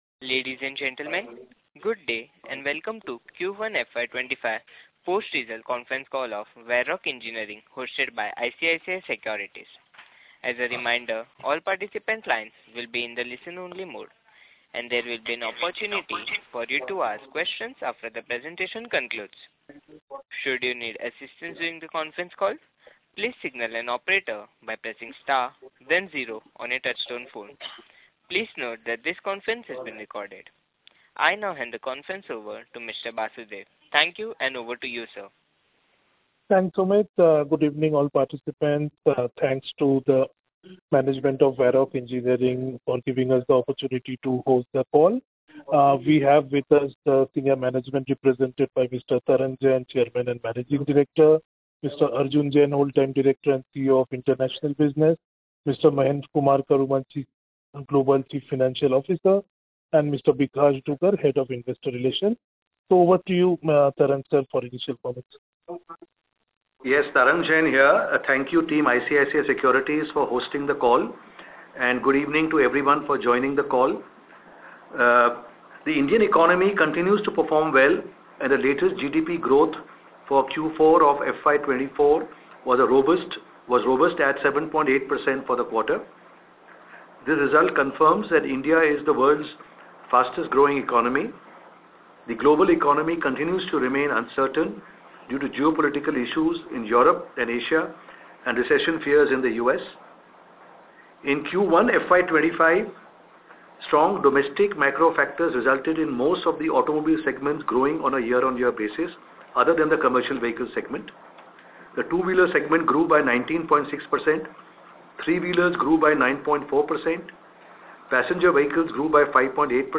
Investor Call Recording